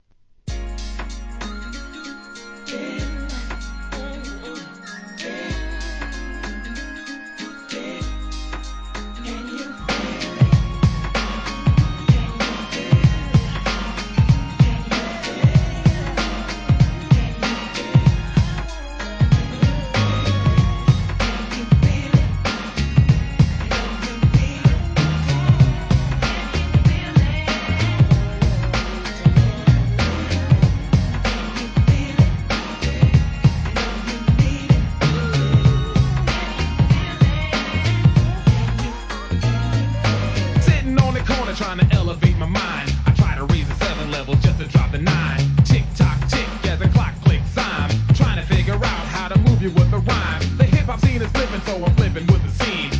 HIP HOP/R&B
綺麗なシンセ・メロディーにフィメール・ヴォーカルをフックに配した1993年のメロ〜作品!!